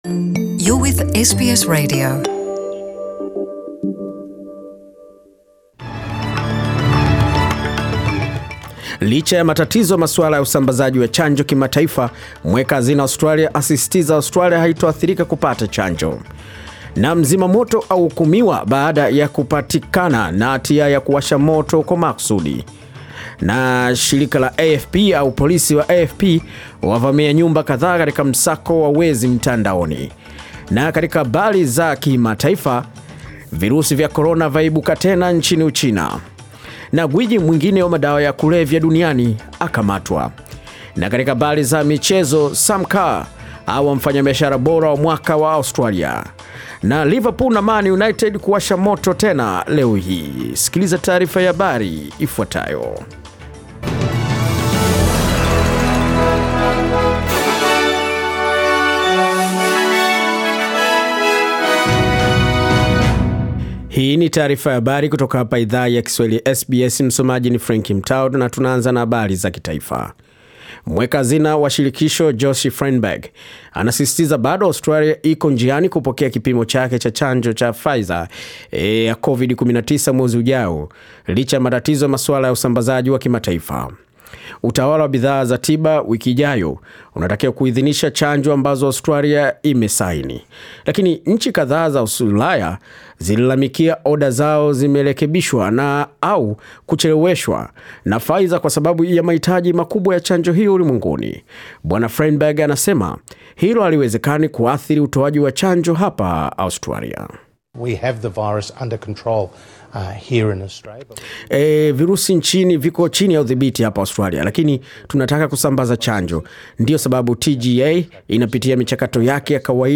Taarifa ya habari 24 Januari 2021